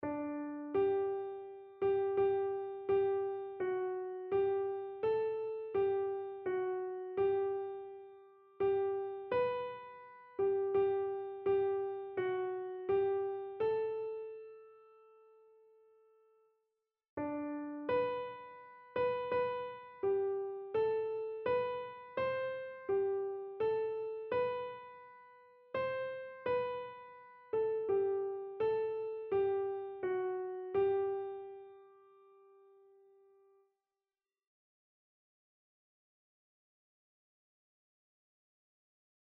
Einzelstimmen (4 Stimmen gemischt)
• Sopran [MP3] 613 KB